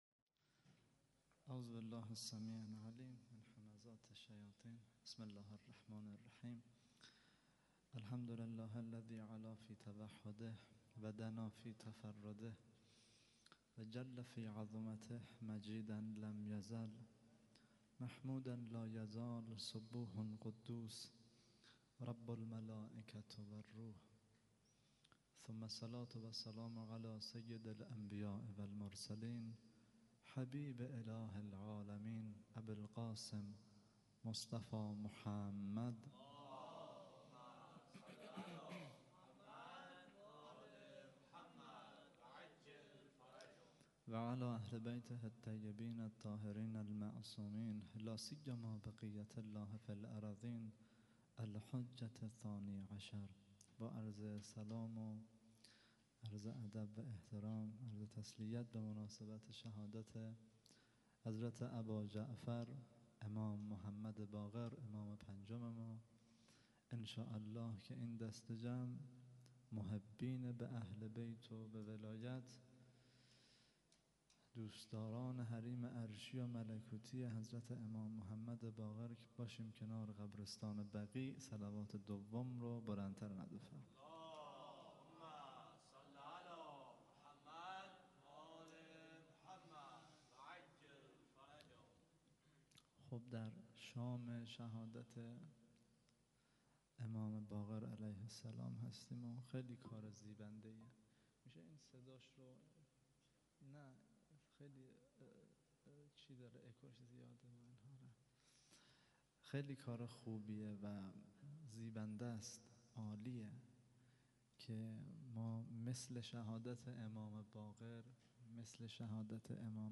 0- سخنرانی